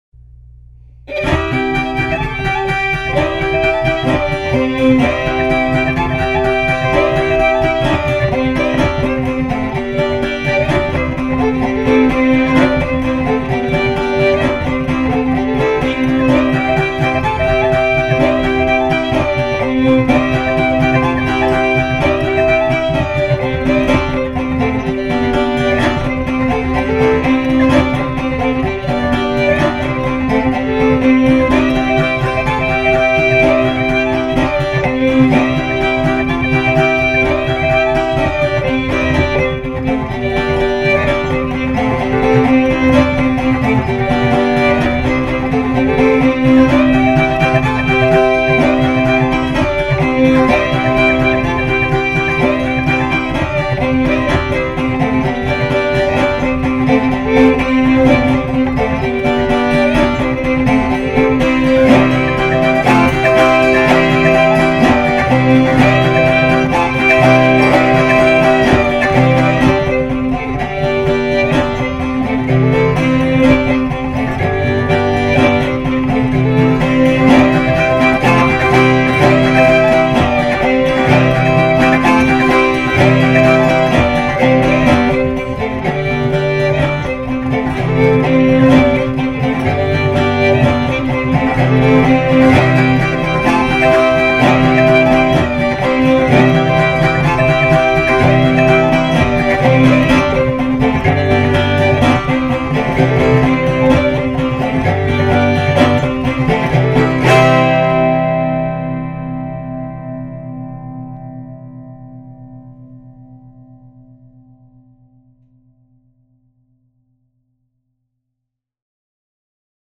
Zimmermann No. 73 autoharp, fiddle
Now an old country fiddle piece played as a duet featuring the Dolgeville No. 73 autoharp and the fiddle, two instruments that go together quite well.